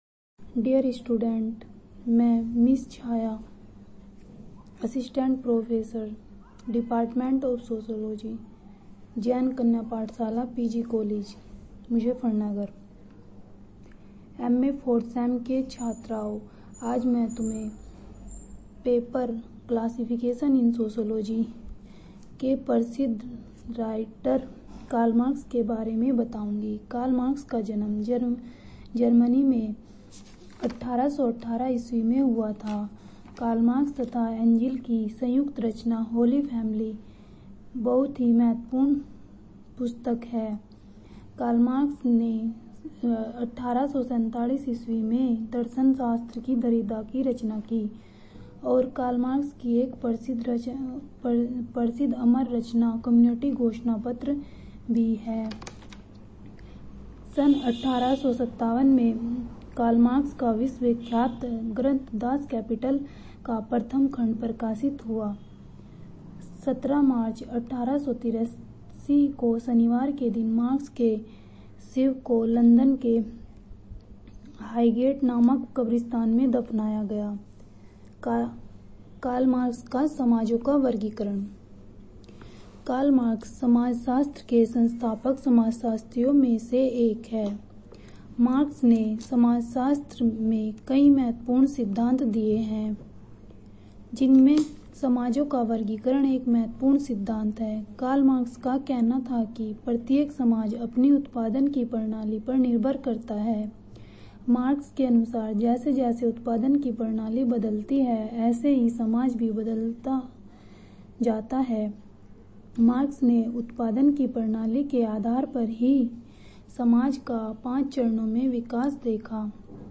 Audio lectures M.A. 4th sem. paper classifation of sociology